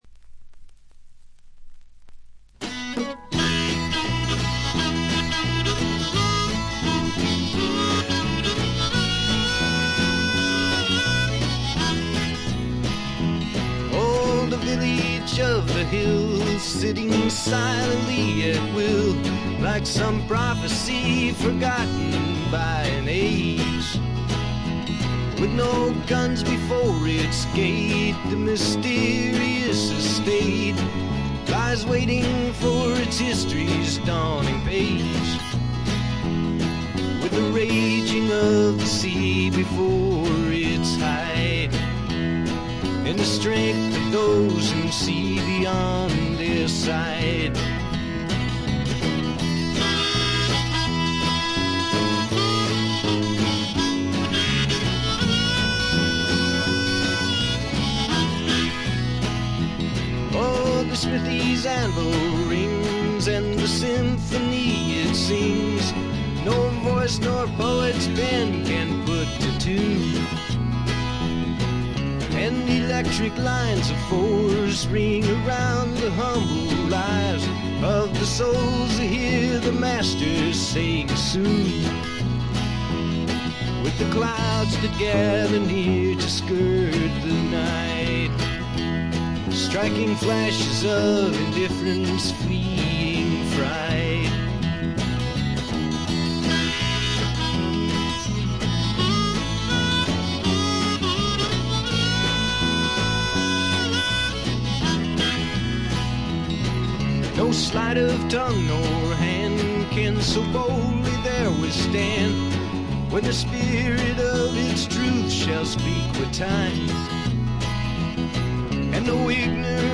これ以外は軽微なバックグラウンドノイズにチリプチ少々。
試聴曲は現品からの取り込み音源です。
Recorded at The Village Recorder